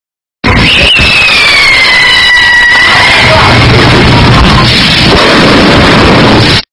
Som de rojão (Completo) – Estourado
Categoria: Sons de memes
som-de-rojao-completo-estourado-pt-www_tiengdong_com.mp3